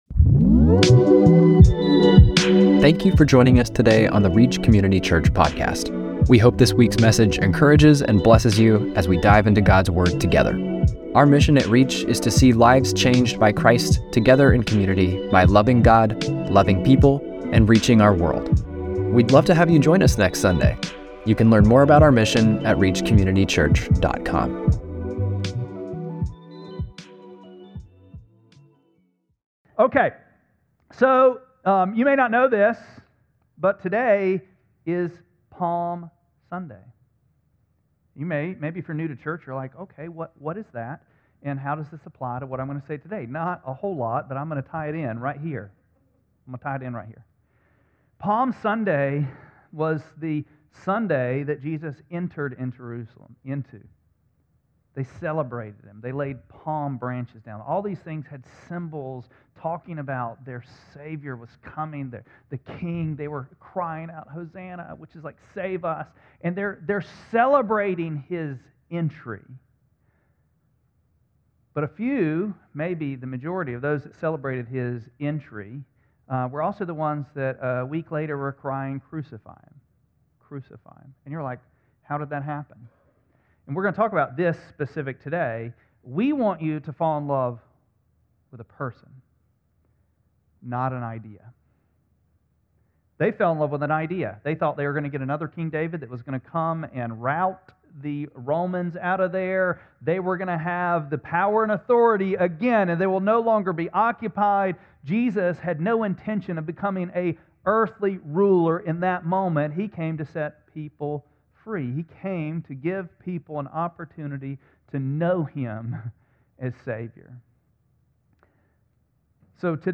3-29-26-Sermon.mp3